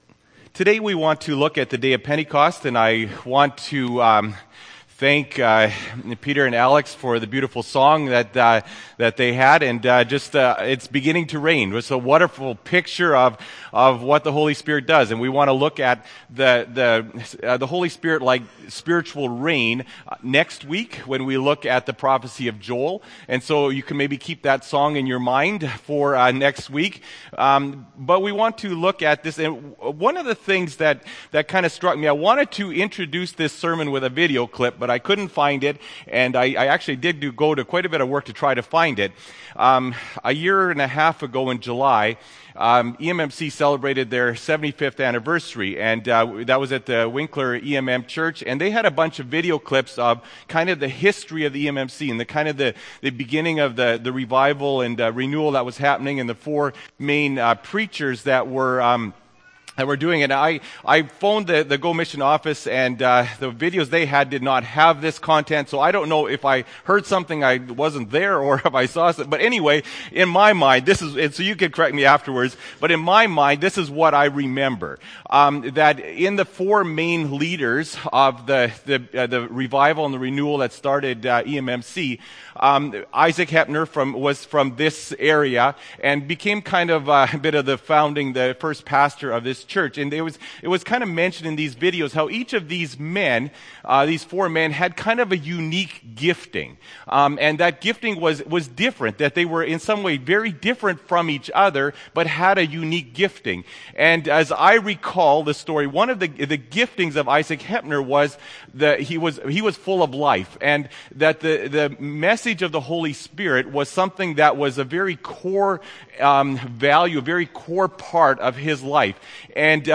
Nov. 10, 2013 – Sermon